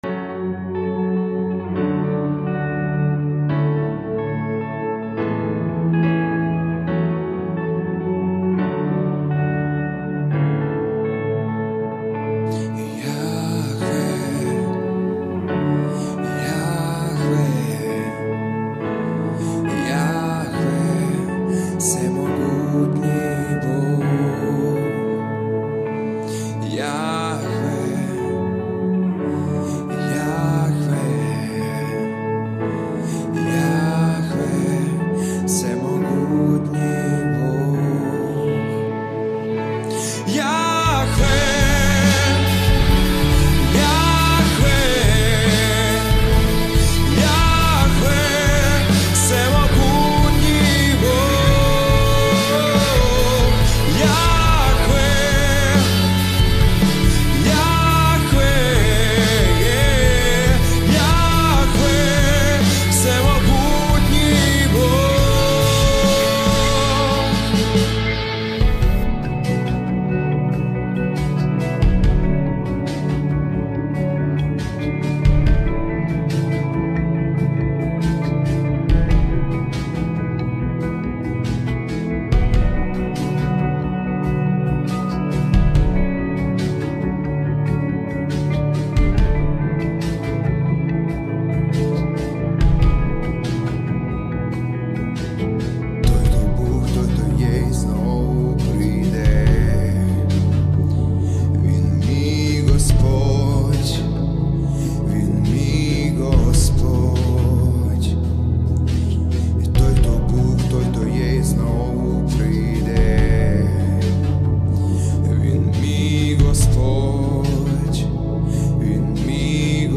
613 просмотров 476 прослушиваний 20 скачиваний BPM: 70